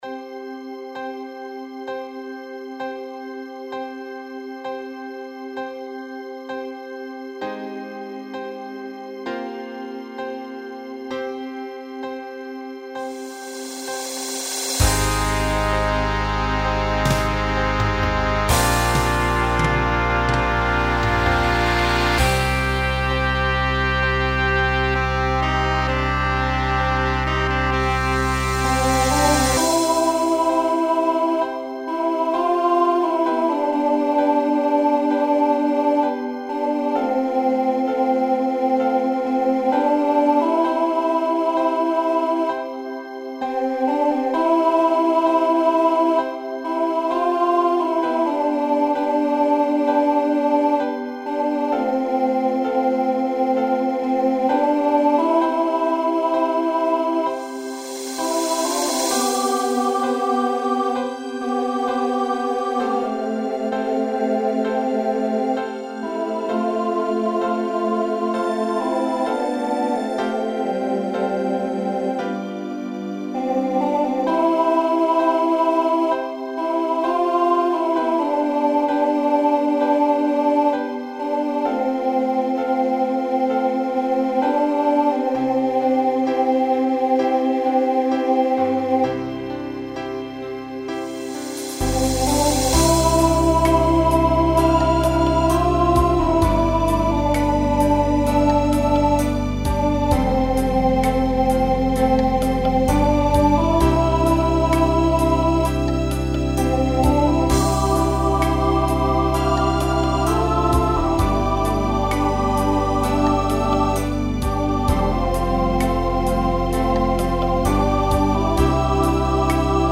Pop/Dance , Swing/Jazz
Ballad Voicing SSA